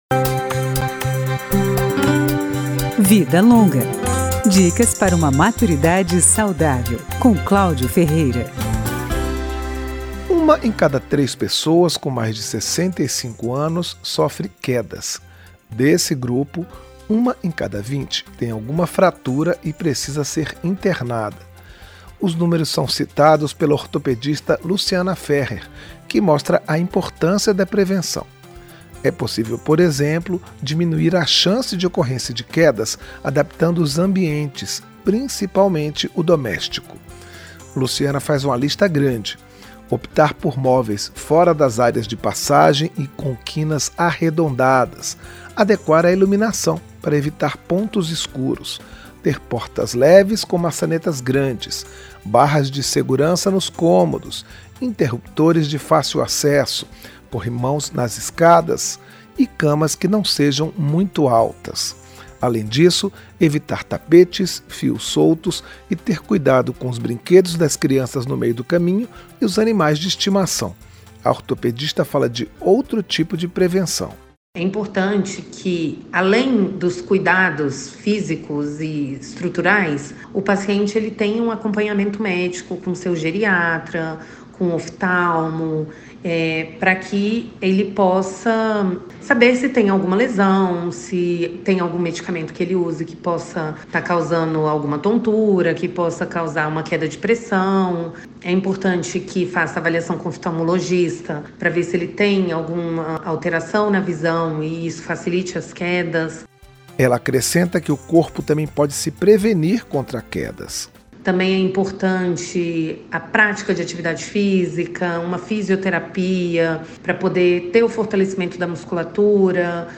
Profissionais de várias áreas falam sobre alimentação, cuidados com a saúde, atividades físicas, consumo de drogas (álcool, cigarro) e outros temas, sempre direcionando seus conselhos para quem tem mais de 60 anos.
Tudo em uma linguagem direta, mas dentro de uma abordagem otimista sobre a terceira idade.